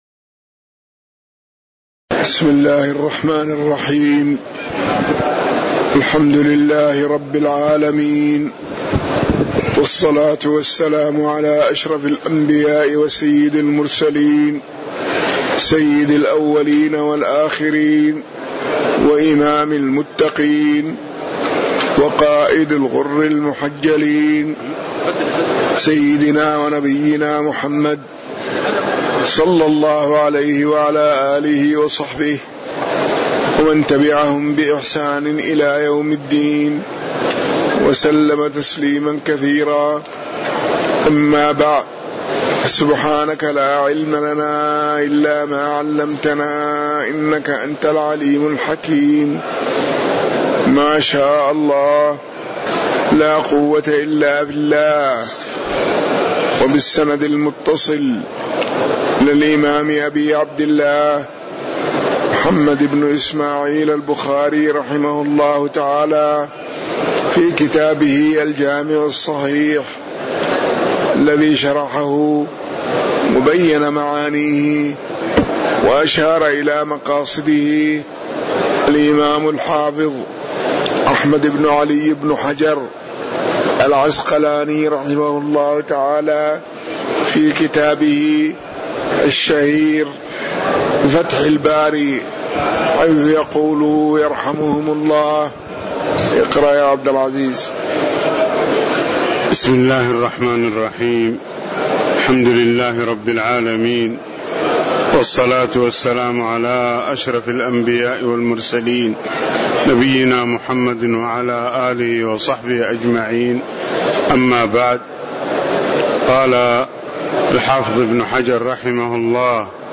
تاريخ النشر ٣ رجب ١٤٣٩ هـ المكان: المسجد النبوي الشيخ